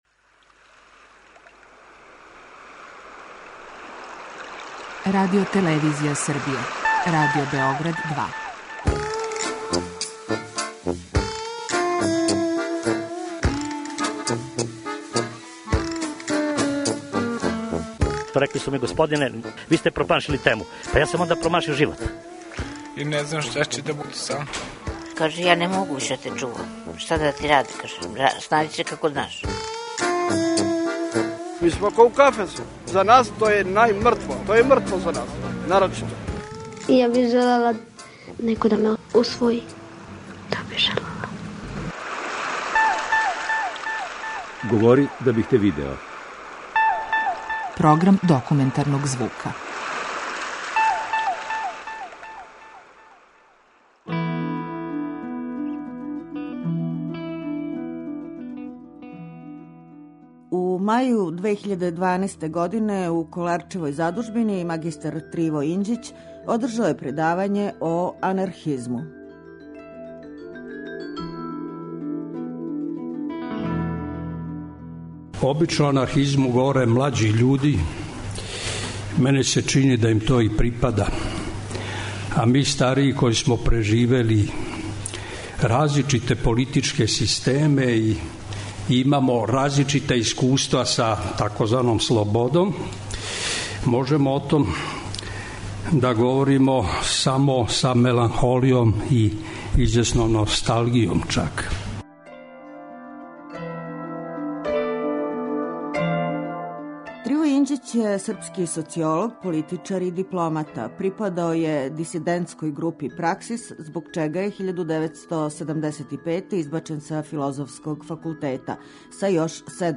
Серијал РЕЧИ У ВРЕМЕНУ - звучни записи предавања са Коларца
преузми : 10.77 MB Говори да бих те видео Autor: Група аутора Серија полусатних документарних репортажа, за чији је скупни назив узета позната Сократова изрека: "Говори да бих те видео".